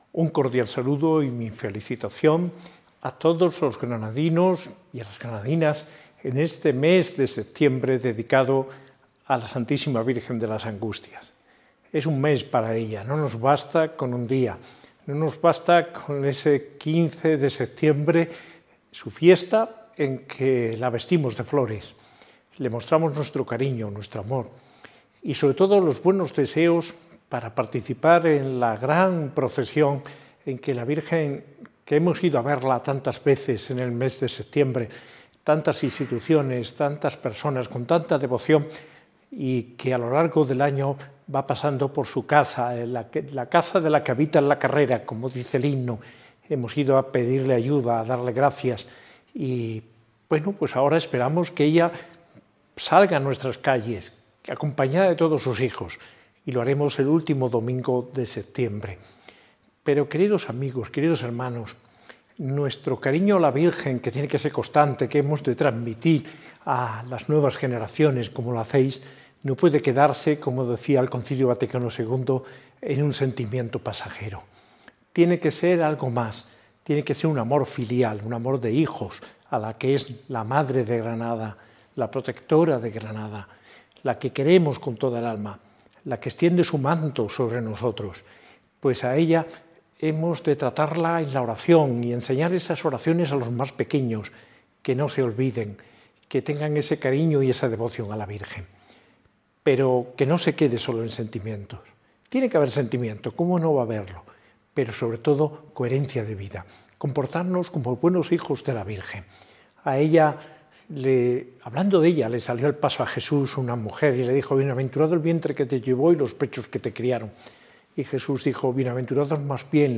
Mensaje de Mons. José María Gil Tamayo, arzobispo de Granada, con motivo de la celebración este mes de la Patrona de Granada y su Archidiócesis, Nuestra Señora de las Angustias.